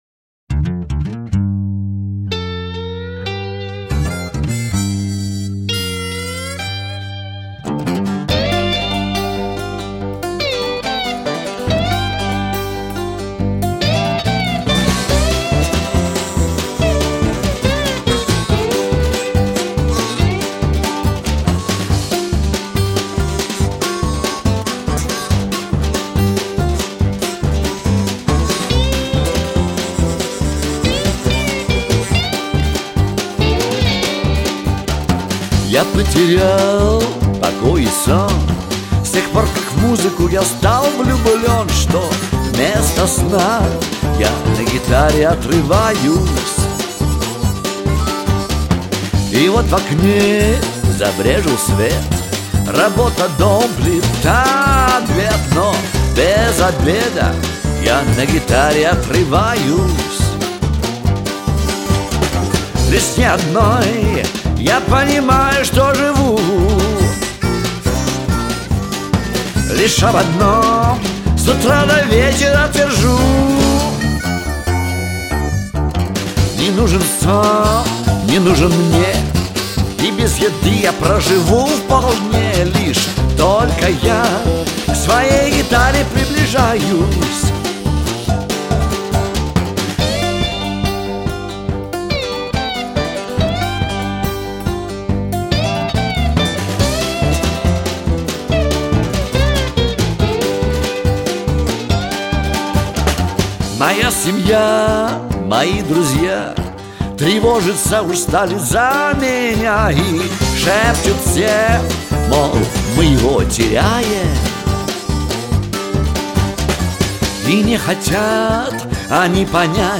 гитара, вокал
скрипка